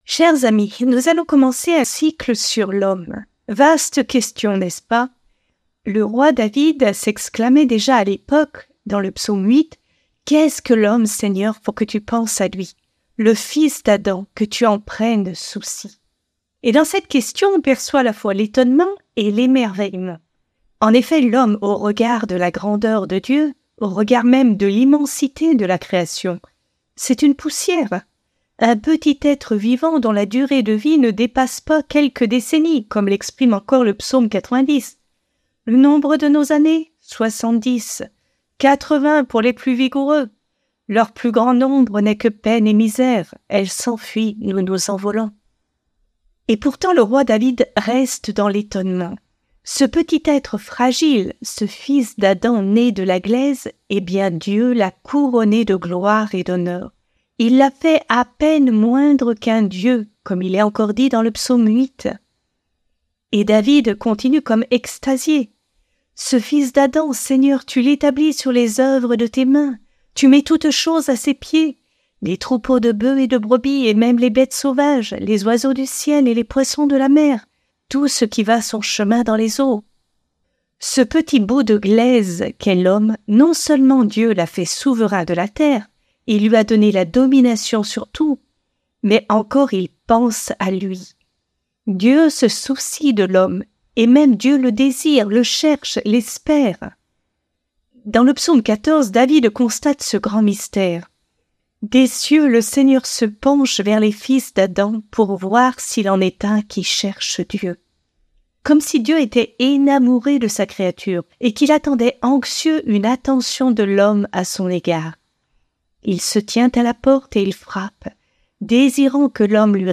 Conférence de la semaine